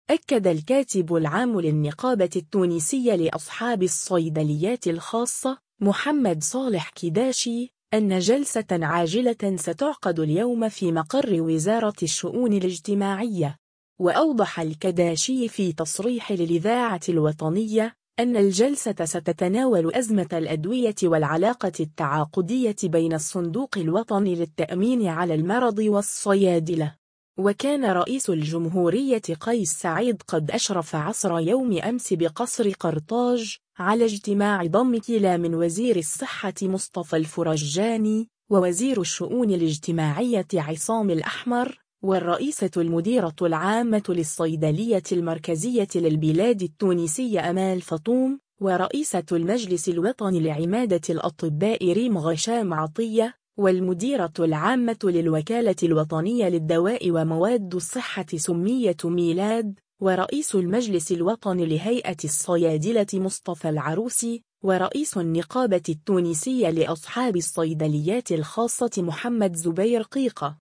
تصريح للاذاعة الوطنية